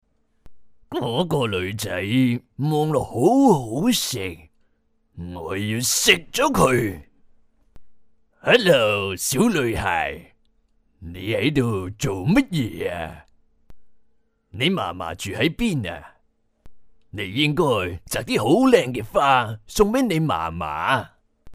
男声 Male Voice-公司名
男粤13 港式粤语广式粤语 人物角色-怪物-奸诈版